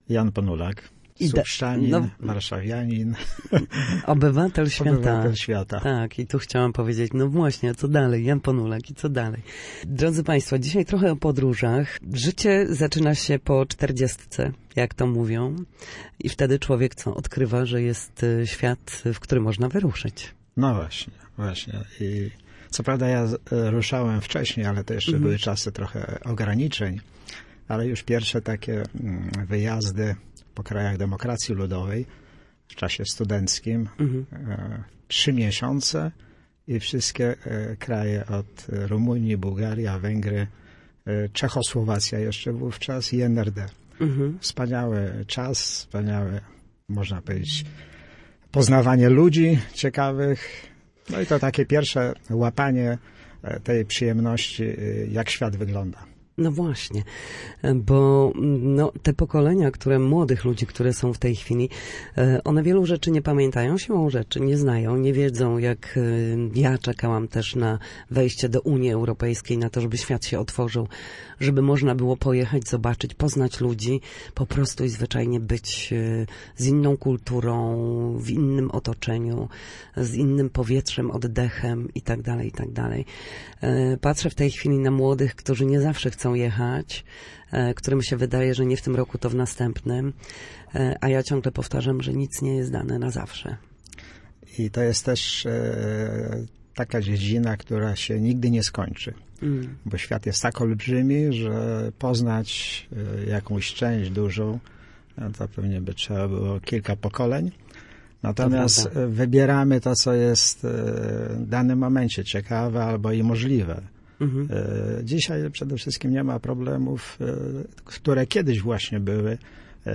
Gościem w Studiu Słupsk